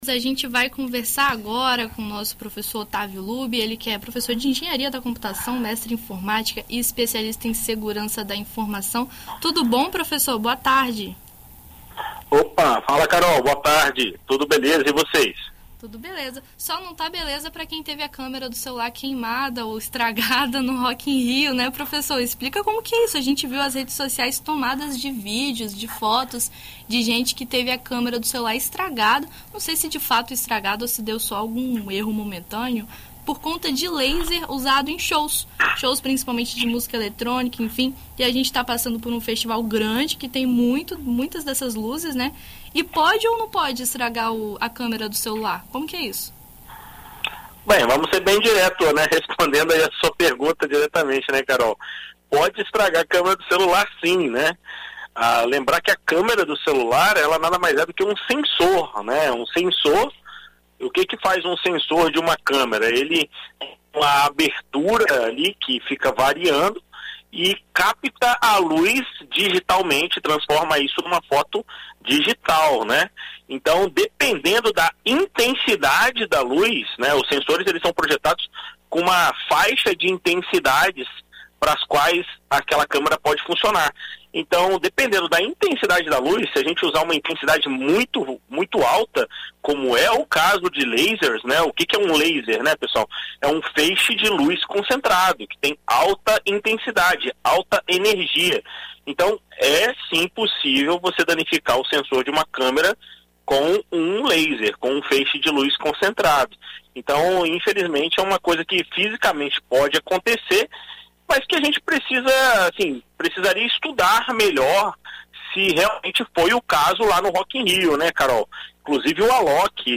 Em entrevista à BandNews FM ES nesta terça-feira (06)